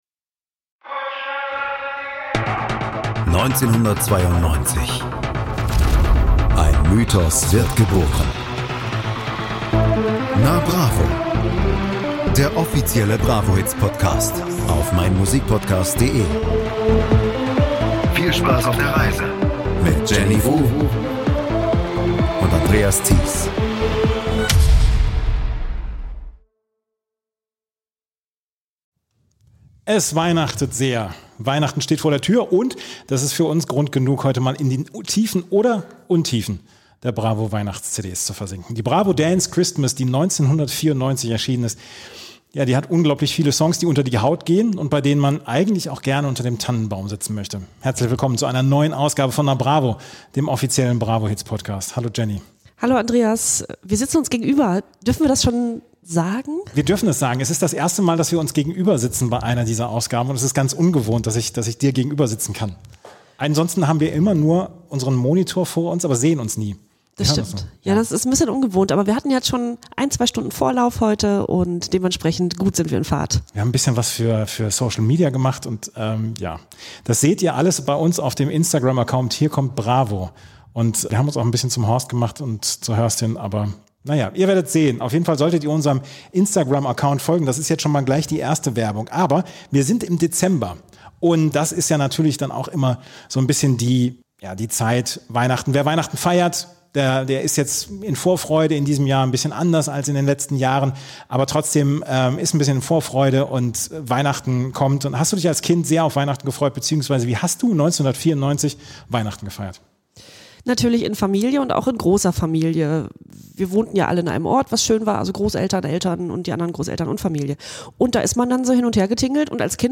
Und da man Weihnachten auch im Podcast hören soll, haben sie die Folge auch gleich in einem Kirchenschiff aufgenommen. Deswegen der Hall. Es geht in der neuen Episode um die Bravo Dance X-Mas aus dem Jahr 1994.